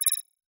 Holographic UI Sounds 1.wav